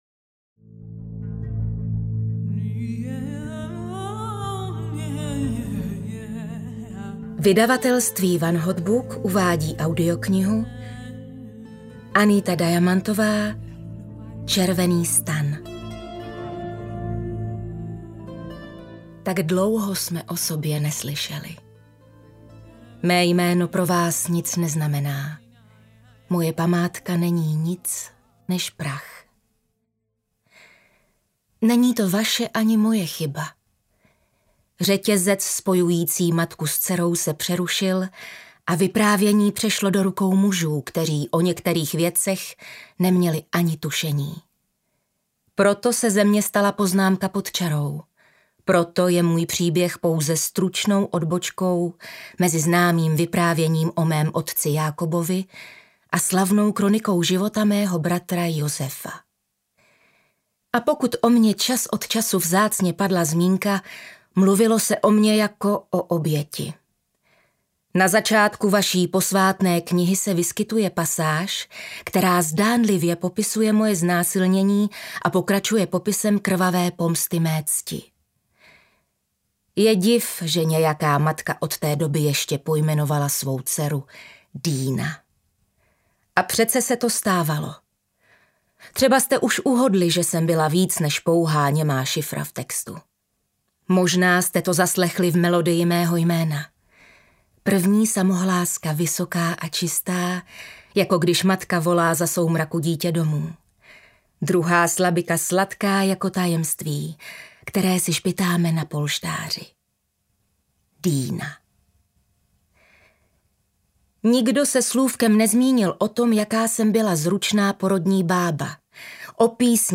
Červený stan audiokniha
Ukázka z knihy
• InterpretKateřina Winterová